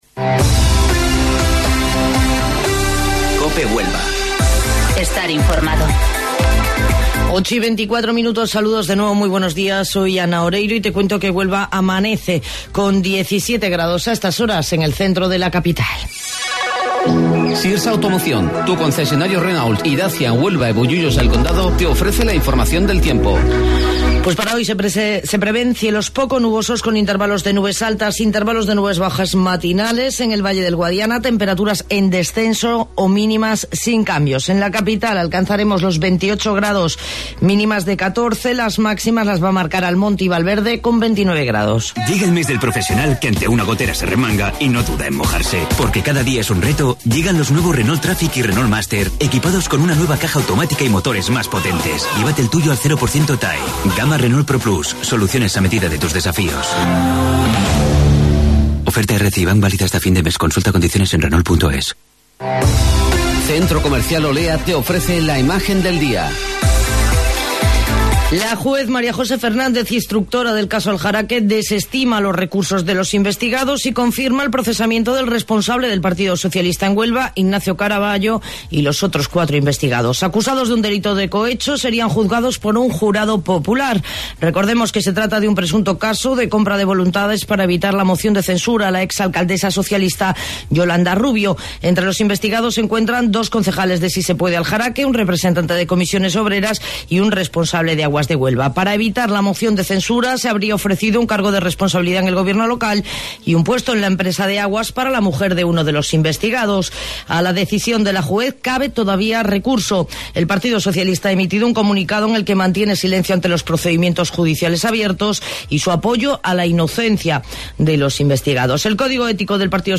AUDIO: Informativo Local 08:25 del 2 de Octubre